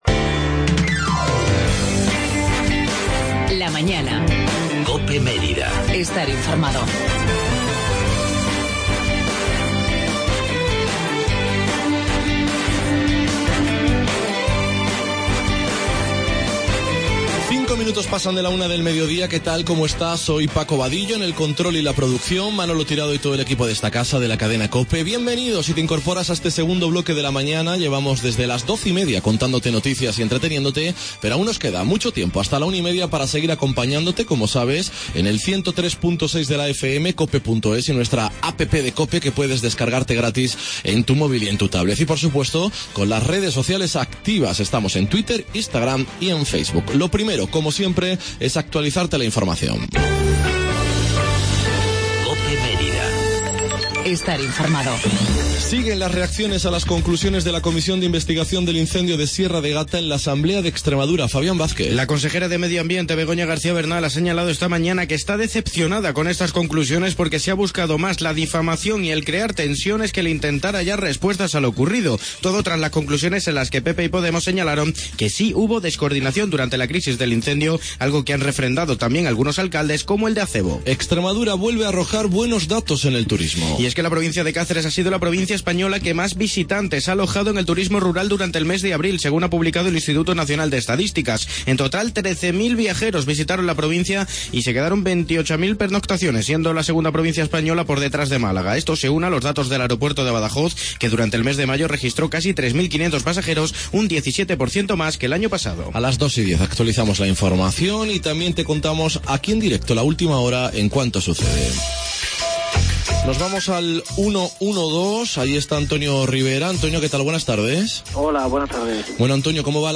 TERTULIA EUROCOPE LA MAÑANA 16-06-16